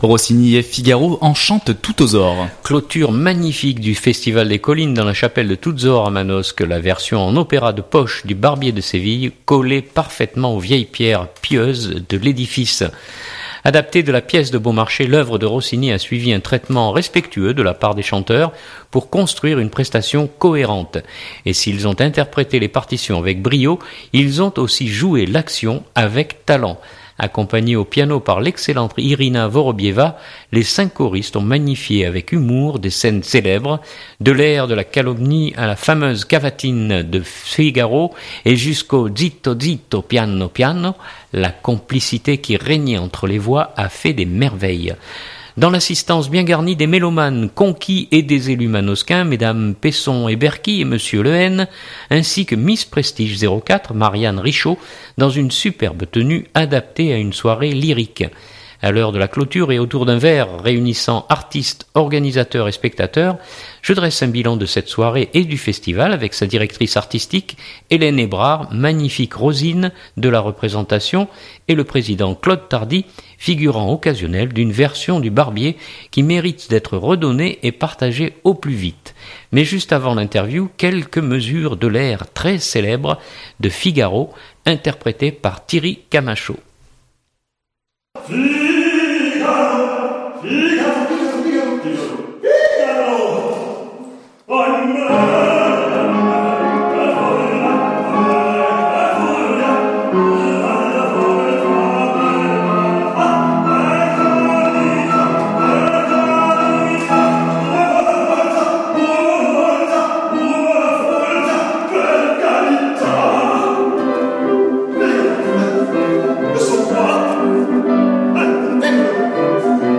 Clôture magnifique du Festival des Collines dans la Chapelle de Toutes-Aures à Manosque. La version en opéra de poche du Barbier de Séville collait parfaitement aux vieilles pierres pieuses de l’édifice.
Mais juste avant l’interview, quelques mesures de l’air très célèbre de Figaro